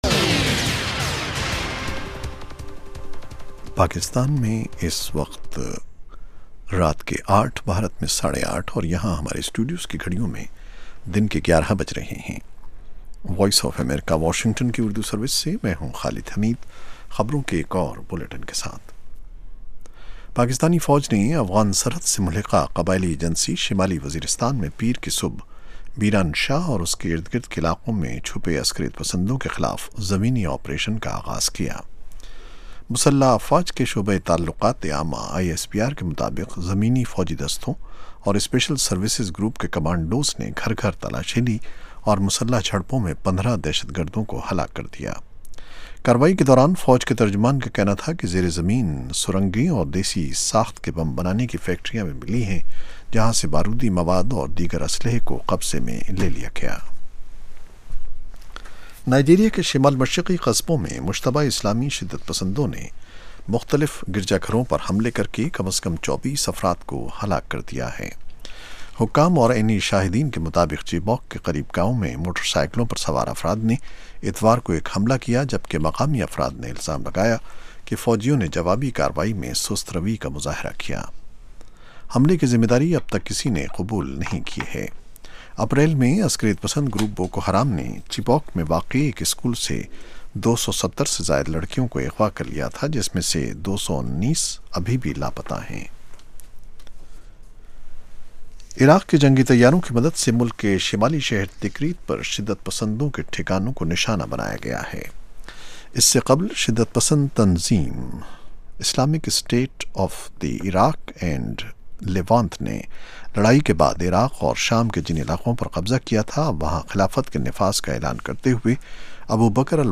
In The News: 8:00PM PST ایک گھنٹے دورانیے کے اس پروگرام میں خبروں کے علاوہ مہمان تجزیہ کار دن کی اہم خبروں کا تفصیل سے جائزہ لیتے ہیں اور ساتھ ہی ساتھ سننے والوں کے تبصرے اور تاثرات بذریعہ ٹیلی فون پیش کیے جاتے ہیں۔